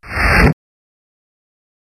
Sweep Kick
Sweep Kick sound effect for fight or game sound effects.